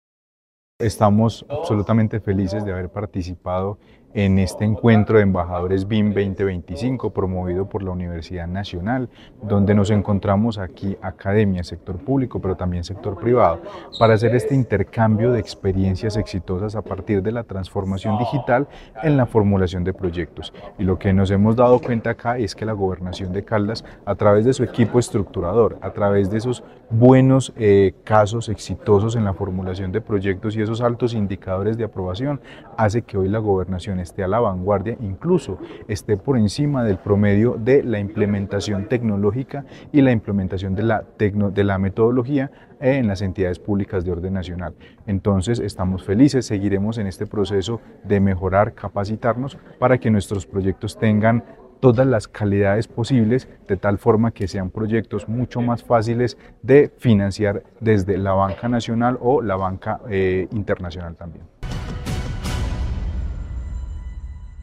Carlos Anderson García, secretario de Planeación de Caldas